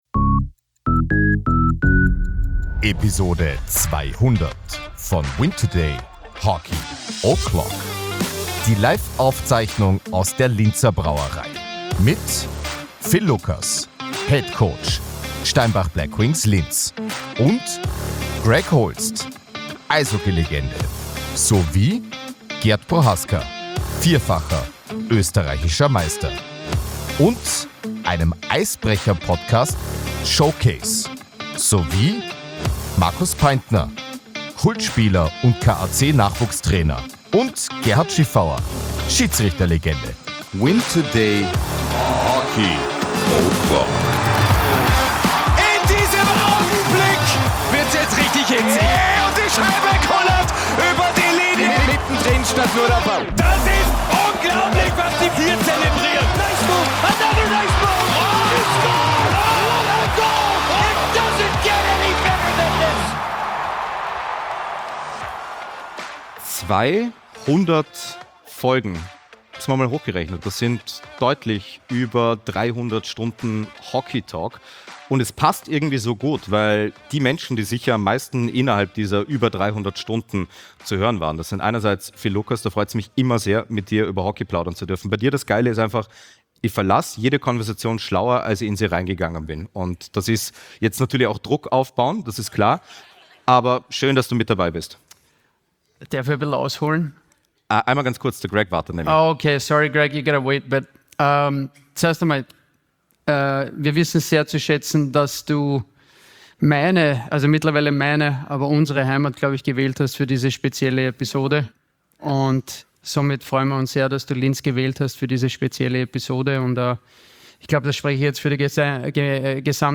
win2day Hockey O‘Clock - 200 - Live aus der Linzer Brauerei